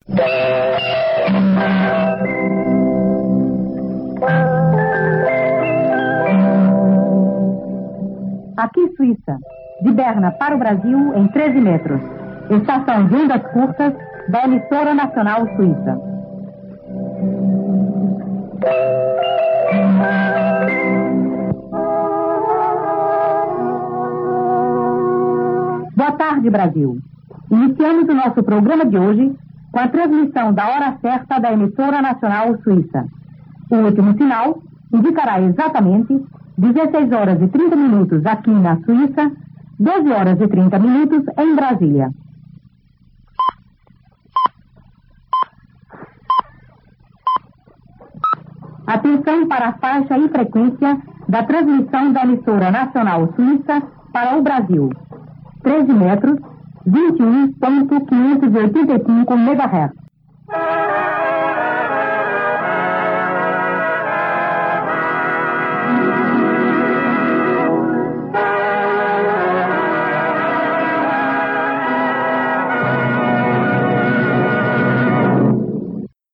Áudio ilustrativo sobre qualidade da recepção do programa SRI no Brasil em 1970